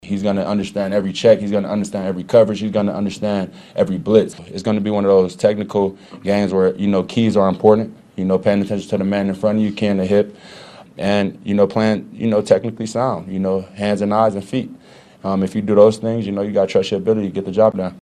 Chiefs defensive end Frank Clark says they will need to play technically sound.